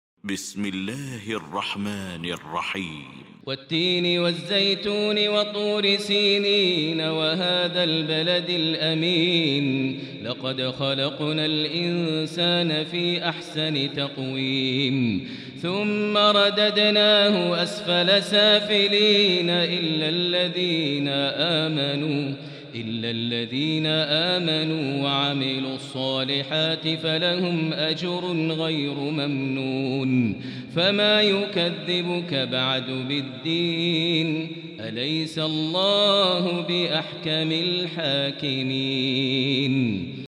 المكان: المسجد الحرام الشيخ: فضيلة الشيخ ماهر المعيقلي فضيلة الشيخ ماهر المعيقلي التين